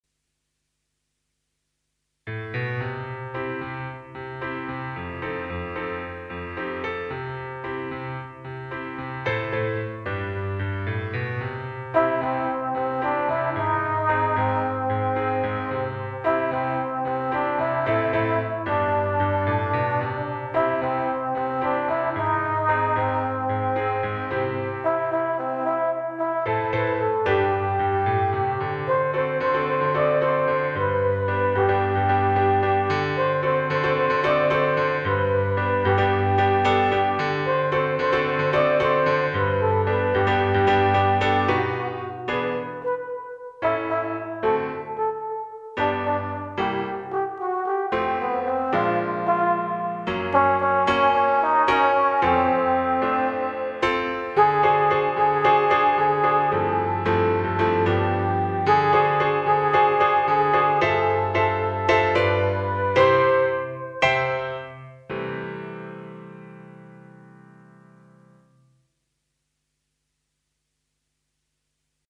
Instrumentation:Melody C, Bb, Eb, pno accompaniment.
Accessible pieces for Solo Instrument with keyboard